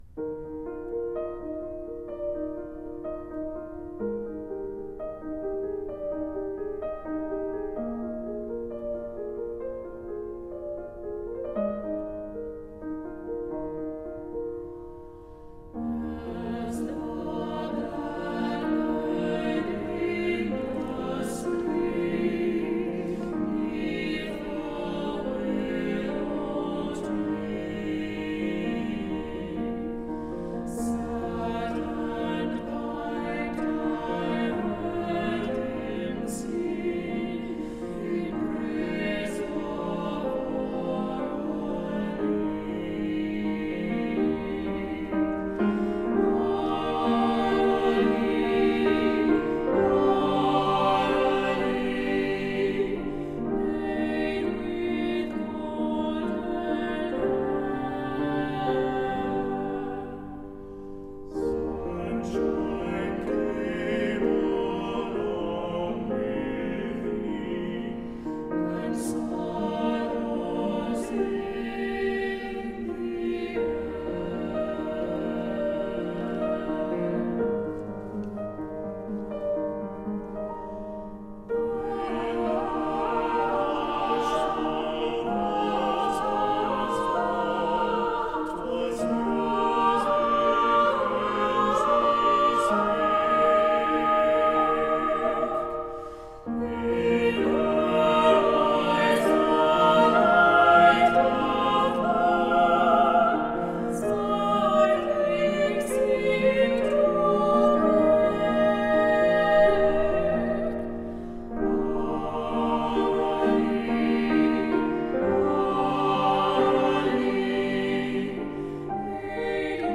Voicing: SA(T)B
Instrumentation: piano
Text: traditional American folk song
choral series